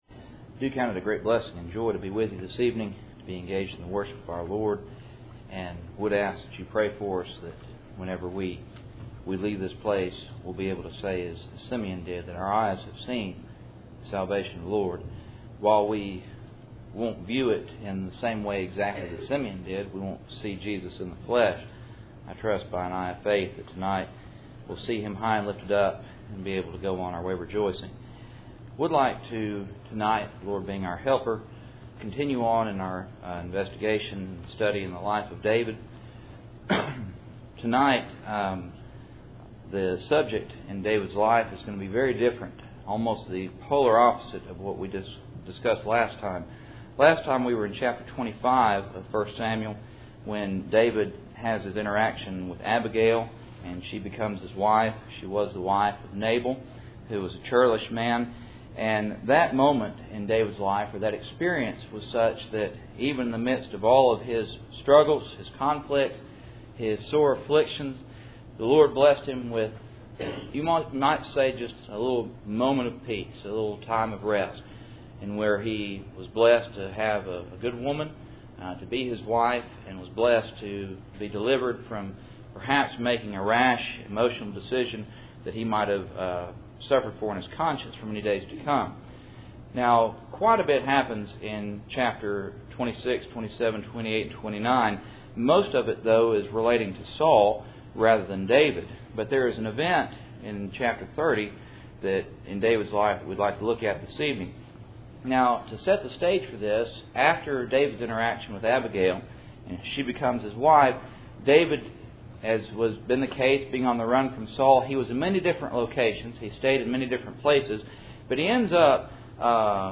1 Samuel 30:1-6 Service Type: Cool Springs PBC Sunday Evening %todo_render% « Predestinated